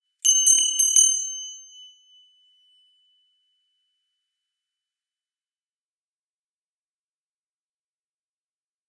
熊よけ鈴の効果音
チリリーンと綺麗な音が鳴ります。
和風効果音84.『熊よけ鈴④』